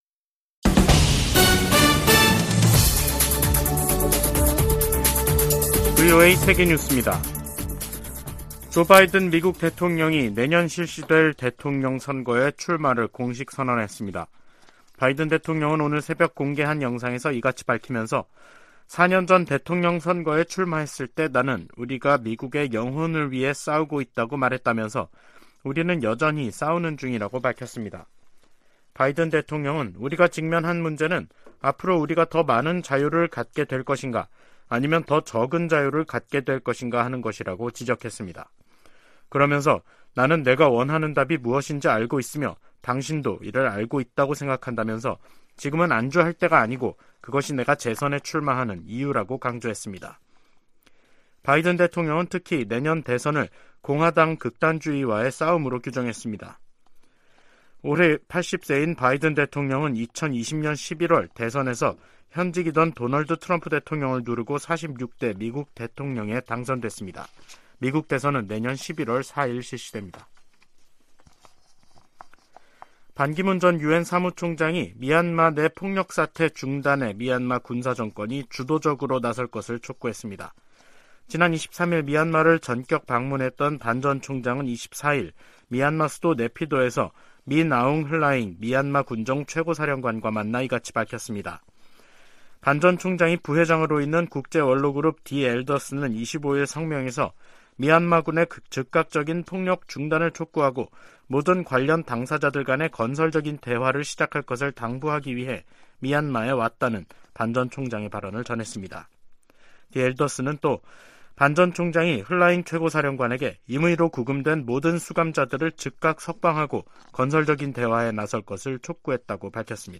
VOA 한국어 간판 뉴스 프로그램 '뉴스 투데이', 2023년 4월 25일 3부 방송입니다. 미국 국빈 방문 일정을 시작한 윤석열 한국 대통령은 미국과 한국이 '최상의 파트너'라며, 행동하는 동맹을 만들겠다고 강조했습니다. 존 커비 백악관 국가안보회의(NSC) 전략소통조정관은 미국의 확장억제 강화 방안이 미한 정상회담 주요 의제가 될 것이라고 밝혔습니다. 미 상원과 하원에서 윤 대통령의 국빈 방미 환영 결의안이 발의됐습니다.